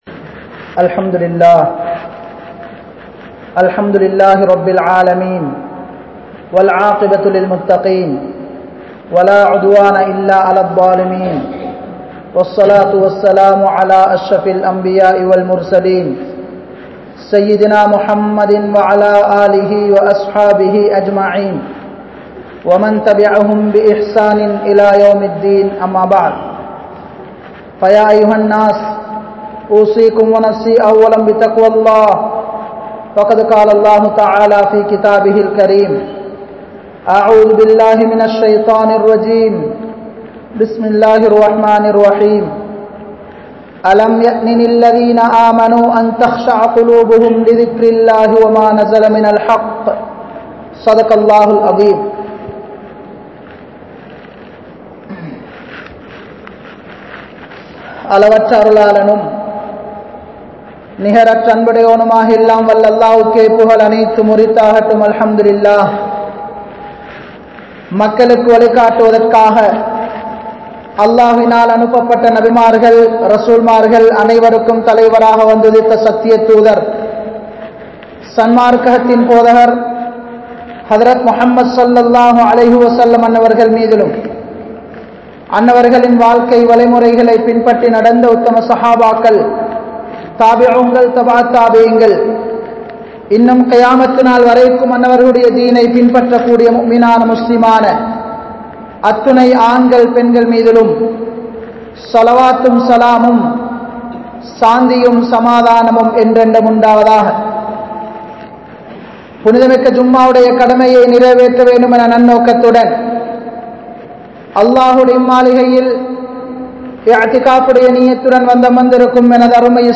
Thirunthi Vaalungal (திருந்தி வாழுங்கள்) | Audio Bayans | All Ceylon Muslim Youth Community | Addalaichenai
Al-Hiqma Jumua Masjith